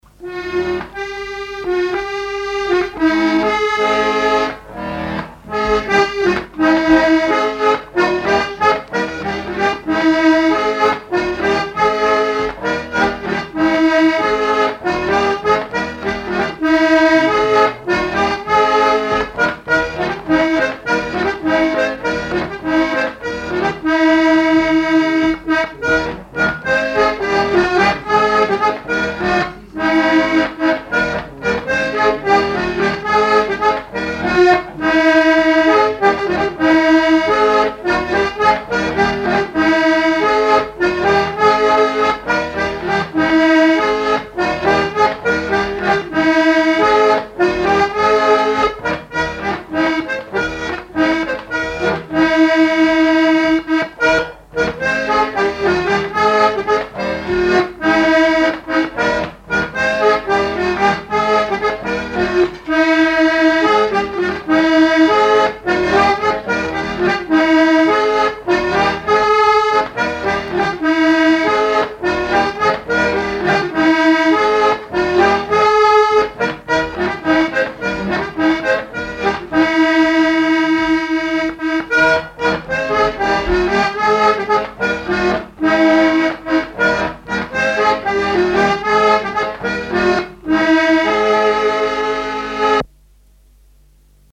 danse : ronde : grand'danse
Pièce musicale inédite